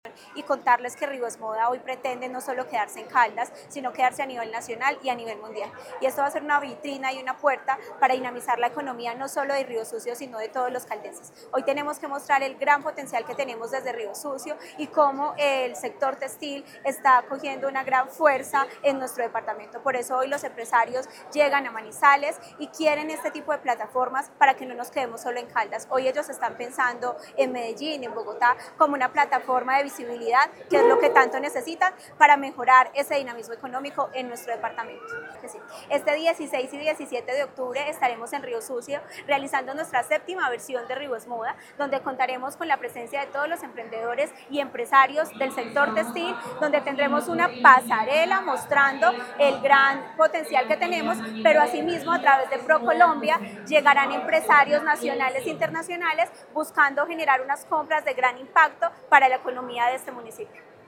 Tania Echeverry Rivera, secretaria de Desarrollo, Empleo e Innovación de Caldas